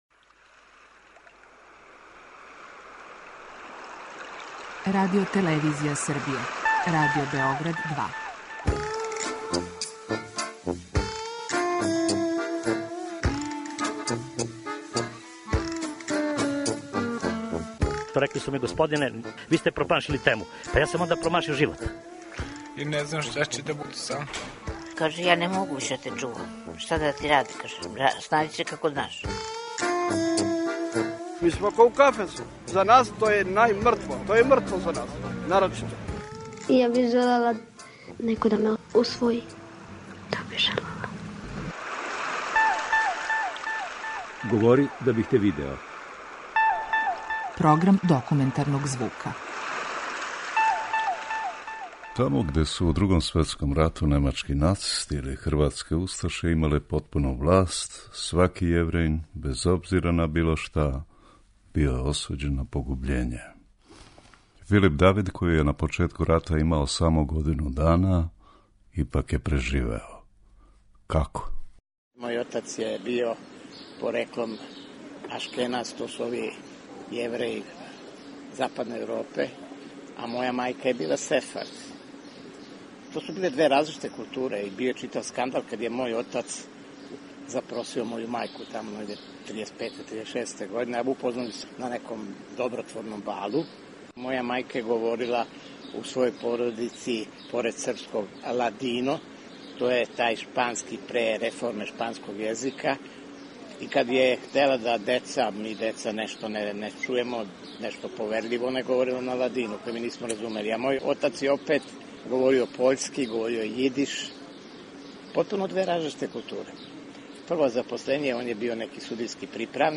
Документарни програм
преузми : 10.80 MB Говори да бих те видео Autor: Група аутора Серија полусатних документарних репортажа, за чији је скупни назив узета позната Сократова изрека: "Говори да бих те видео".
О својим сећањима на страшне догађаје које је његова породица преживела у у Другом светском рату, говориће књижевник Филип Давид .